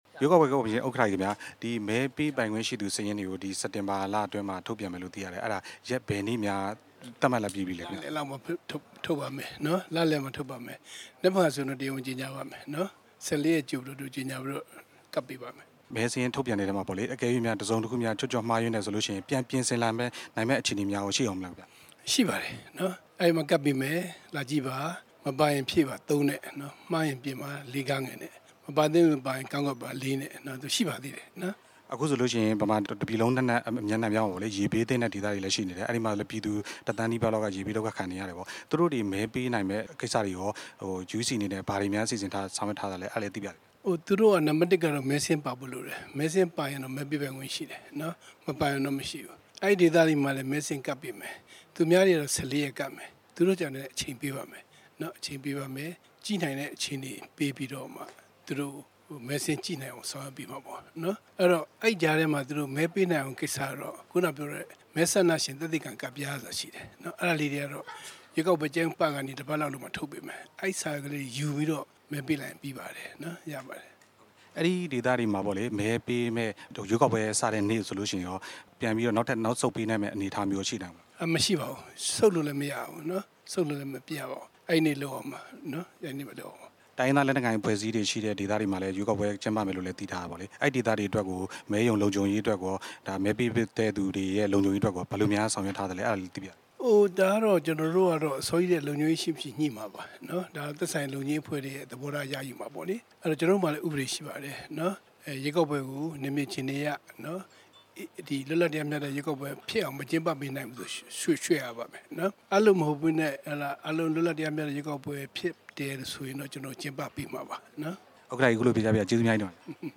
ရန်ကုန်မြို့ Park Royal ဟိုတယ်မှာ ဒီကနေ့ပြုလုပ်တဲ့ ပြည်ထောင်စု ရွေးကောက်ပွဲကော်မရှင်နဲ့ ရွေးကောက် ပွဲ စောင့်ကြည့်လေ့လာမယ့် အရပ်ဘက်လူမှုအဖွဲ့အစည်းတွေ တွေ့ဆုံဆွေးနွေးပွဲအပြီးမှာ RFA ရဲ့ မေးမြန်းချက်ကို ဦးတင်အေးက ဖြေကြားခဲ့တာပါ။